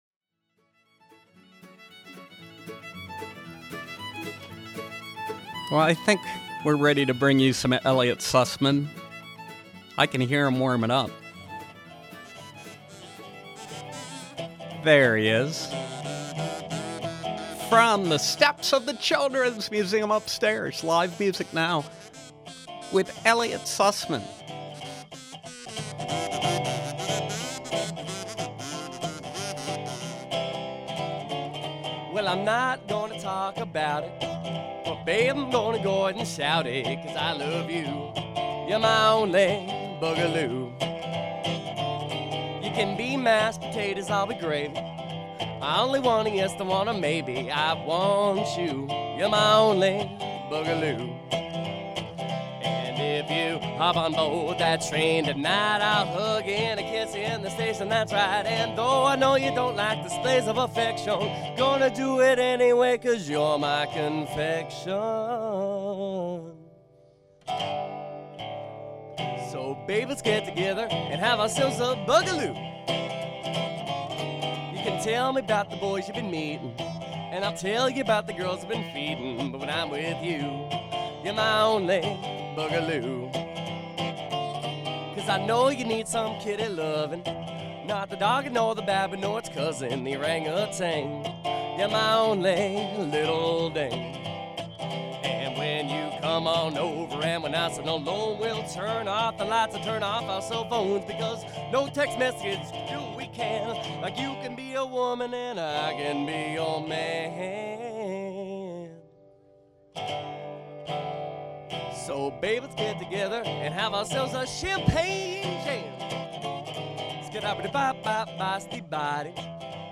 Live Music
performing live from the steps of the Children’s Museum as part of the museum’s 30th Birthday Celebration